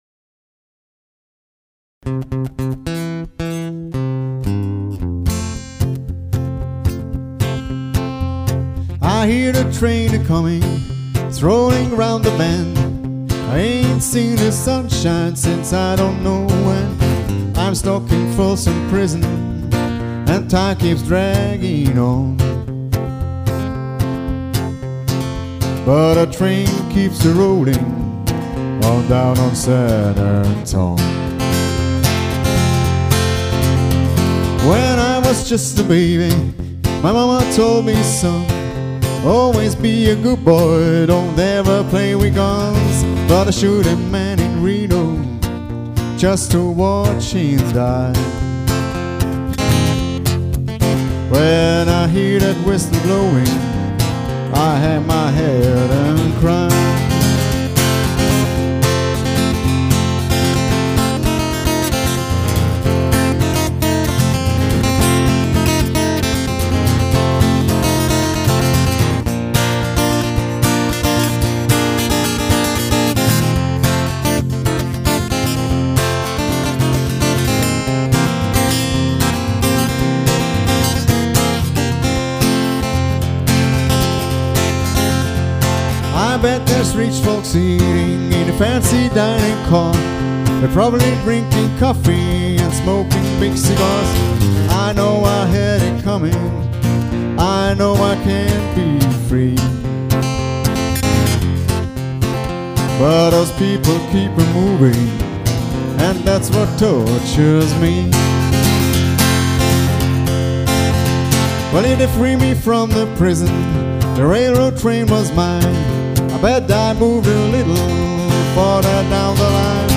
MIT VIEL SOUL BLUESIG GESUNGEN UND EINEM HAUCH VON
COUNTRY, GOSPEL, JAZZ, ROCK'N'ROLL & REGGAE UMWEHT.
Begleitet mit akustischer Gitarre.